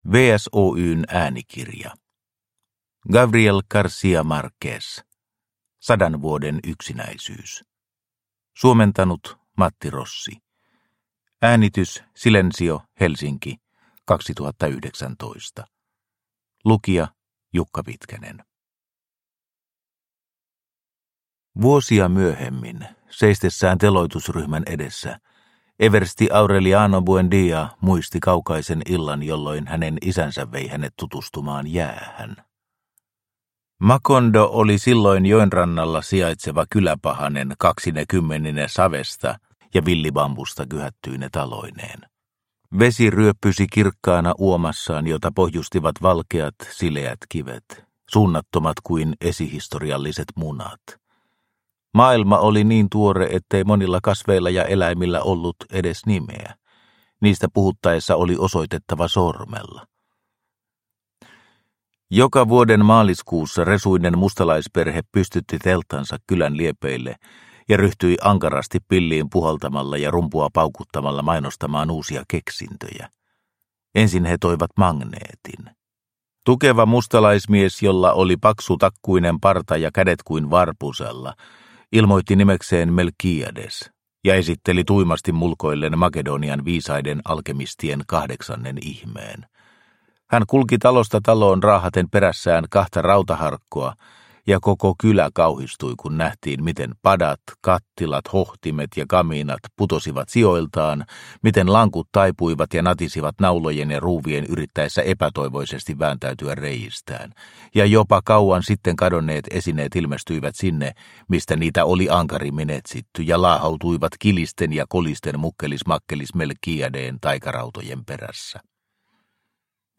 Sadan vuoden yksinäisyys – Ljudbok – Laddas ner